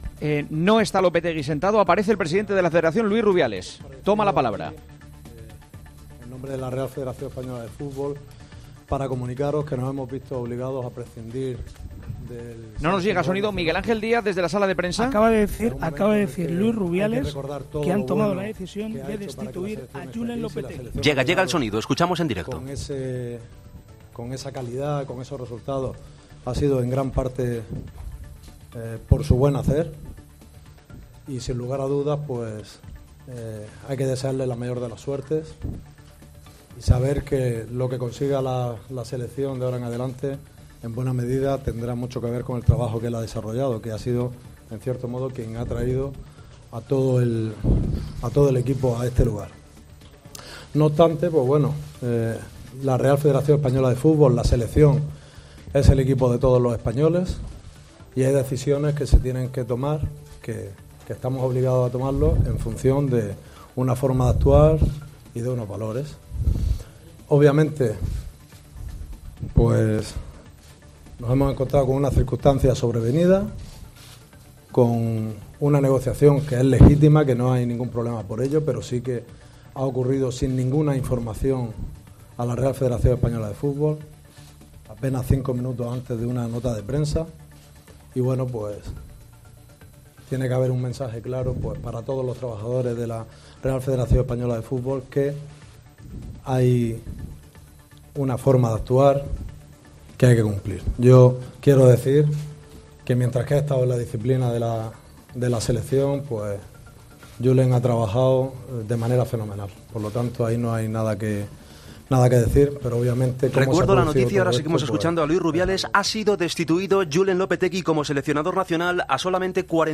AUDIO: Luis Rubiales, presidente de la RFEF, anuncia ante los medios de comunicación la destitución de Julen Lopetegui como seleccionador a dos días...